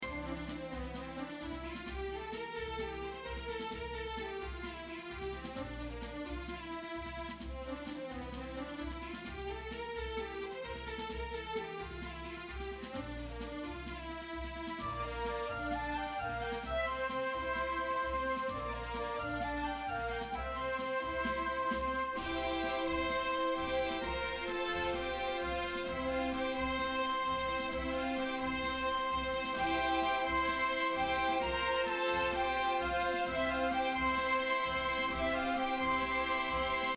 Loop Full Score